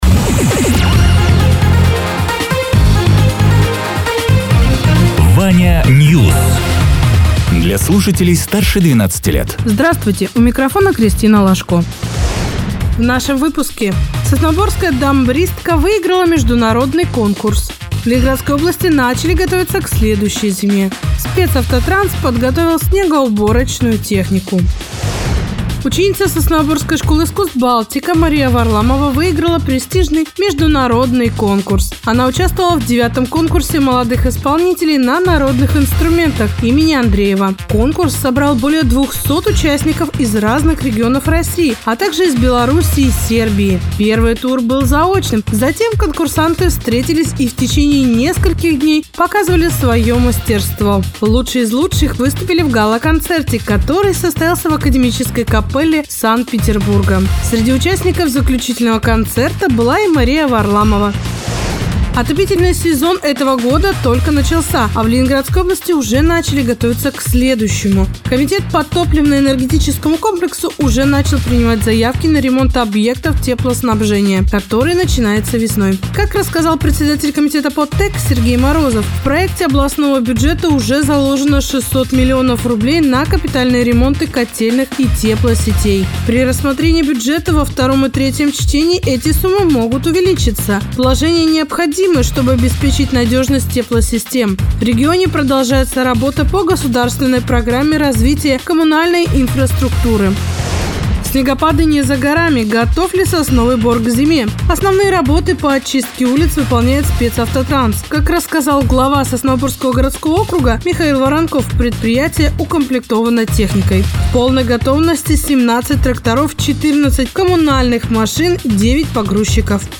Радио ТЕРА 02.04.2026_12.00_Новости_Соснового_Бора